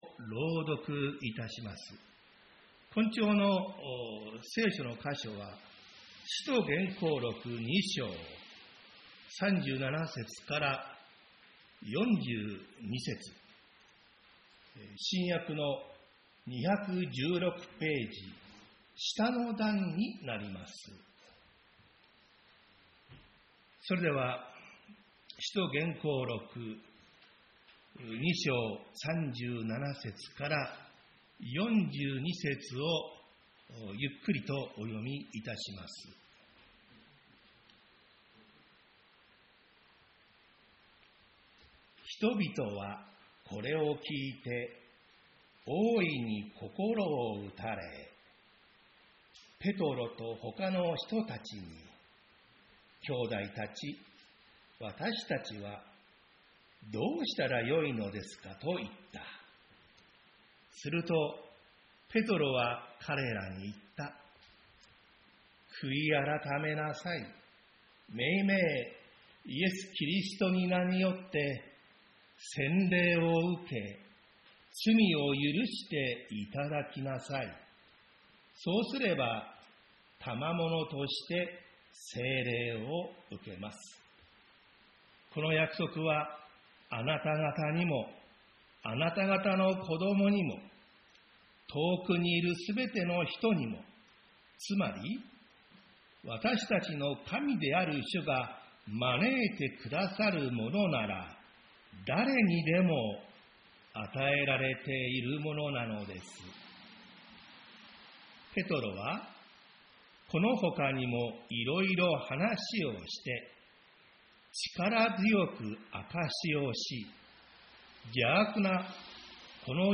栃木県鹿沼市 宇都宮教会
礼拝説教アーカイブ 日曜 朝の礼拝